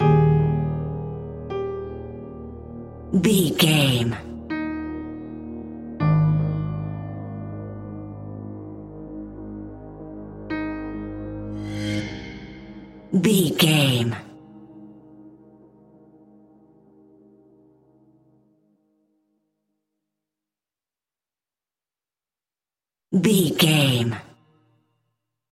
Aeolian/Minor
Slow
scary
ominous
dark
haunting
eerie
melancholy
ethereal
synthesiser
piano
strings
cello
horror music
Horror Pads